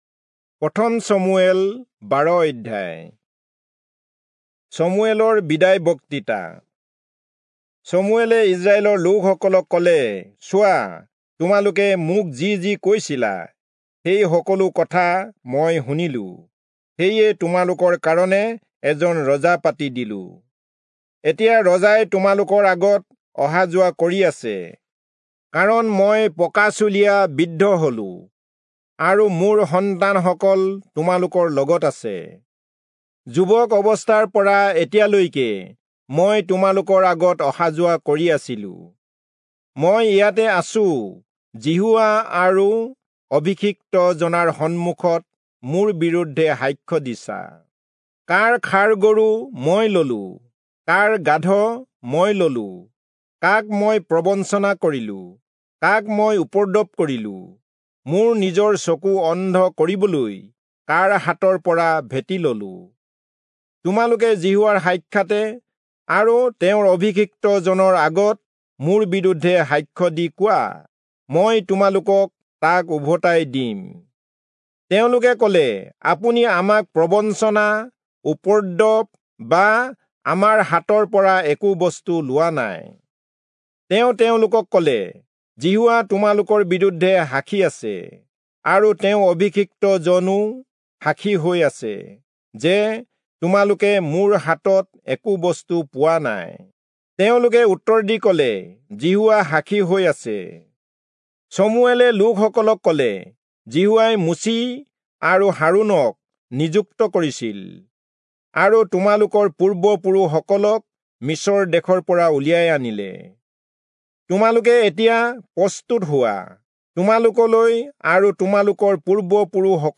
Assamese Audio Bible - 1-Samuel 1 in Ocvta bible version